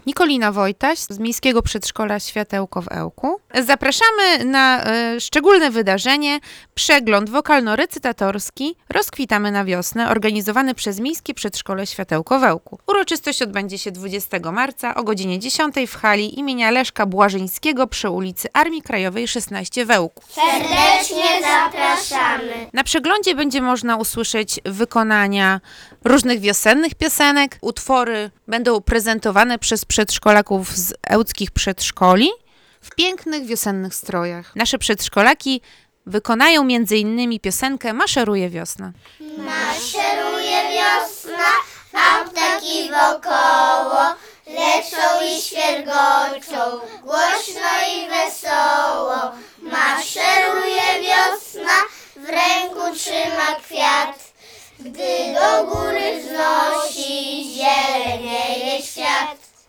A to za sprawą ełckich przedszkolaków, które wystąpią w międzyprzedszkolnym przeglądzie wokalno-recytatorskim „Rozkwitamy na wiosnę”.
wiosennych piosenek i wierszyków w wykonaniu ełckich przedszkolaków